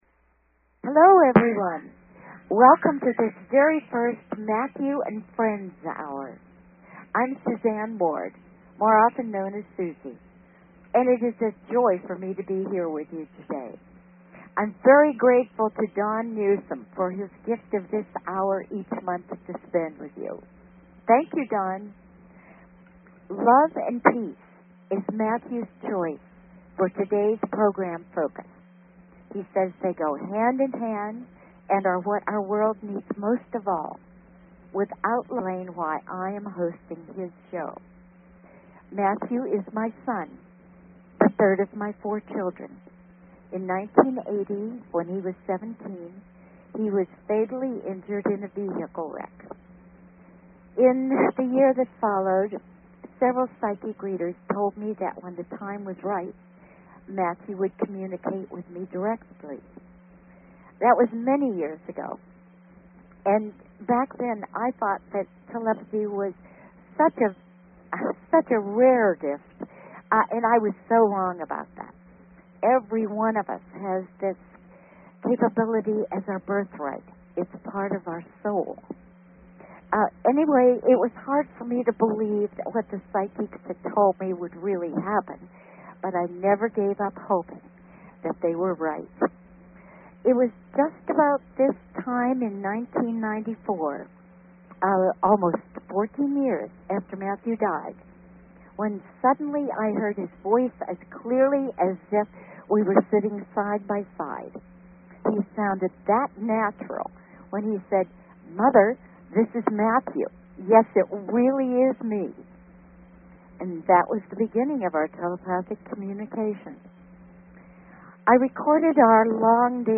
Talk Show Episode, Audio Podcast, Matthew_and_Friends_Hour and Courtesy of BBS Radio on , show guests , about , categorized as